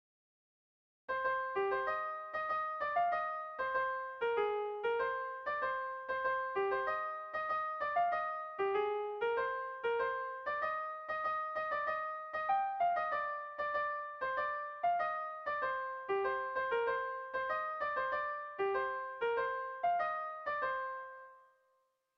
Sentimenduzkoa
Zortziko handia (hg) / Lau puntuko handia (ip)
A1A2BD